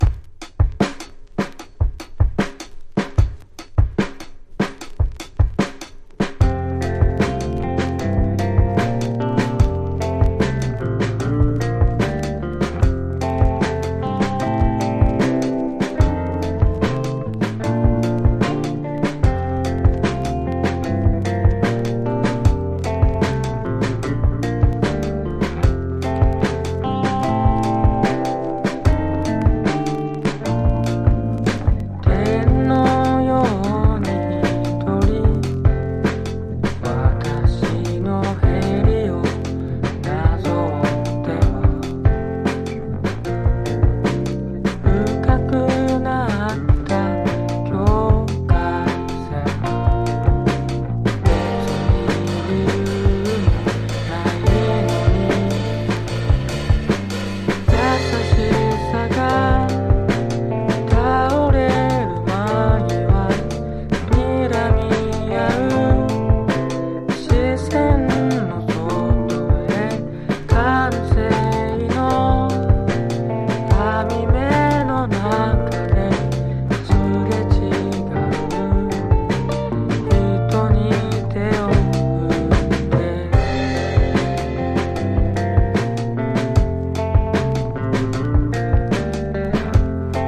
淡々としたサイケデリアが滲む、詩情溢れる白昼夢ストレンジ・ポップ！